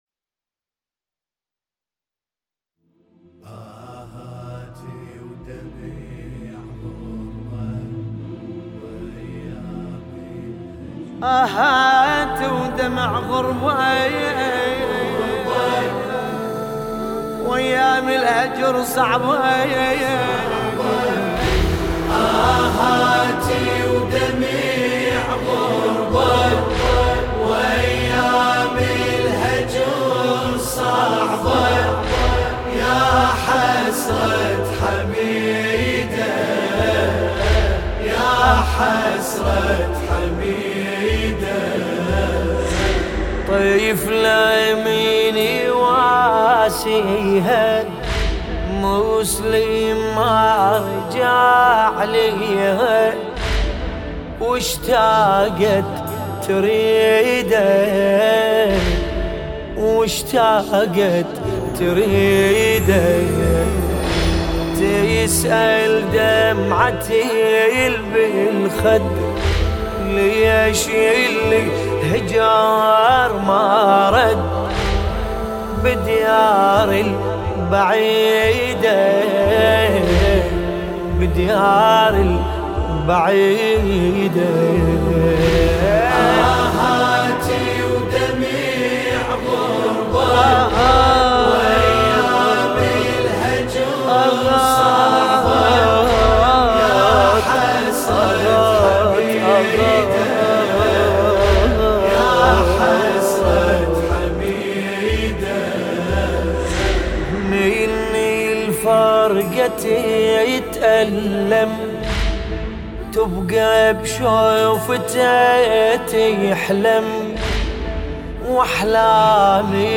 سینه زنی